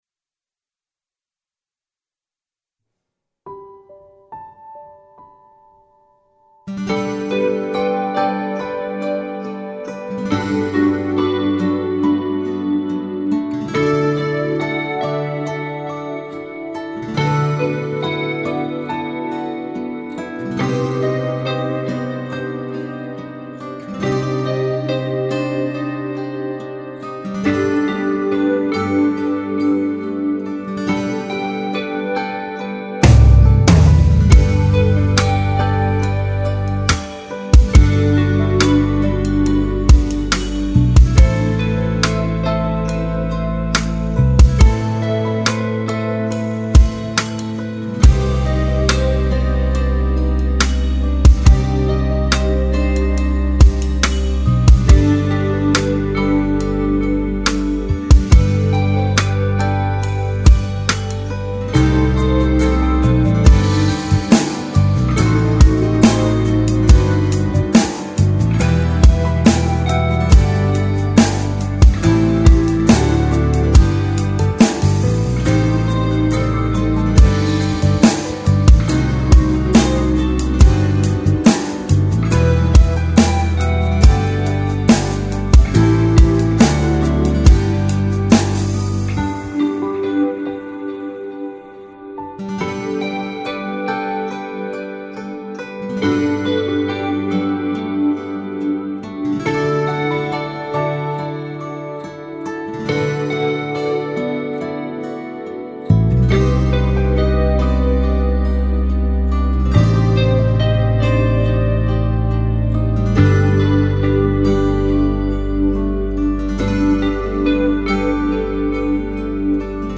سرودهای امام رضا علیه السلام
بی‌کلام